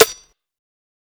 TC2 Snare 26.wav